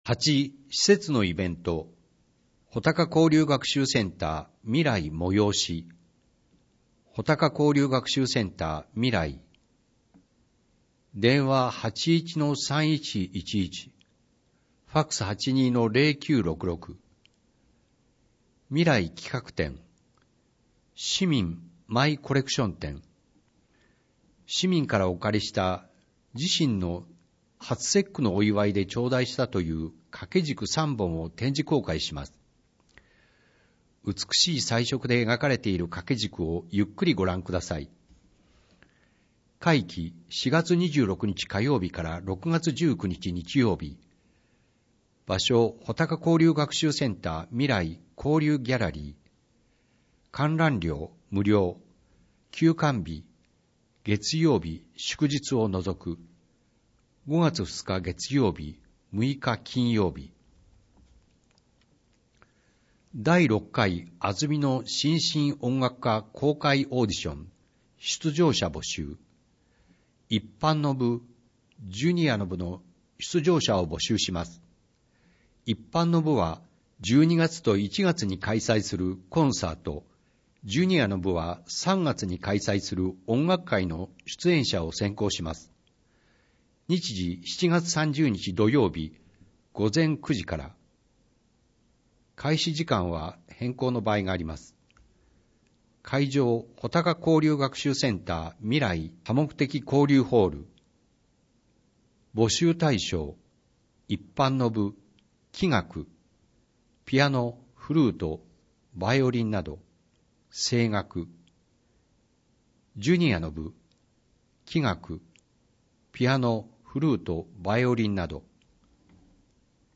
広報あづみの朗読版227号（平成28年4月20日発行) - 安曇野市公式ホームページ
「広報あづみの」を音声でご利用いただけます。この録音図書は、安曇野市中央図書館が制作しています。